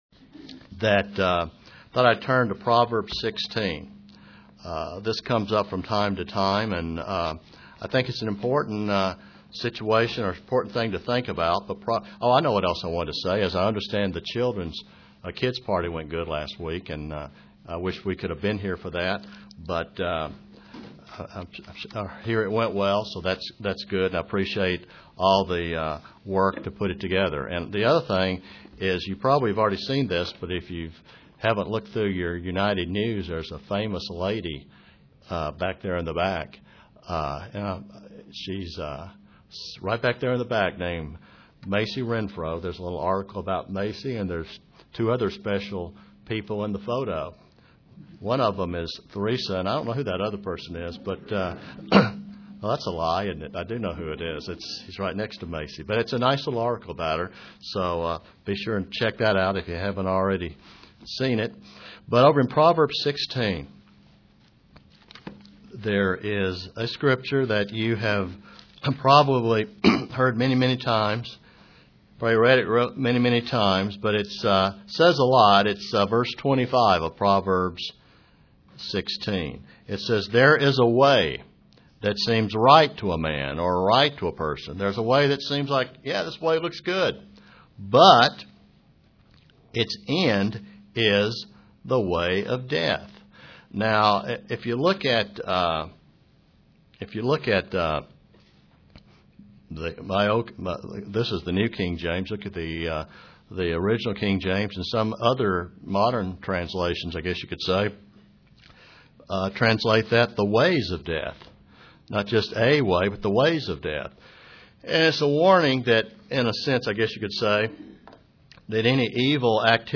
While some may say there are numerous ways to eternal life, the Bible talks about the Way. The Way has specific doctrines a Christian is to follow. (Presented to the Kingsport TN, Church)